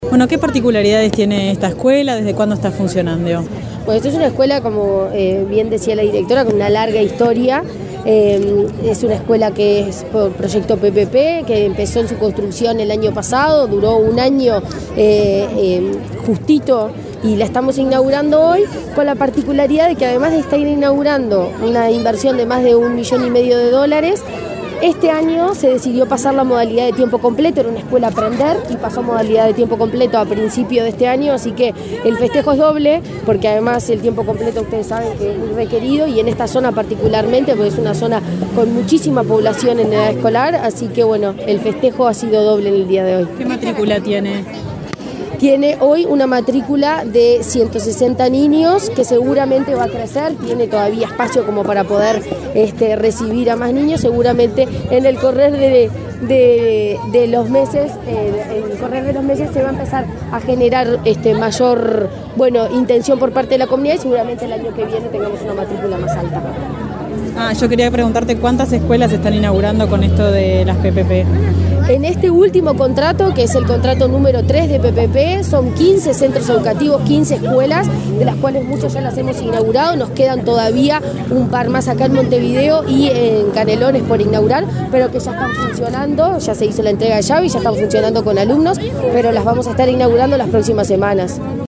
Declaraciones de la presidenta de ANEP, Virginia Cáceres
La presidenta de la Administración Nacional de Educación Pública (ANEP) dialogó con la prensa, luego de inaugurar el edificio de la escuela n.º 289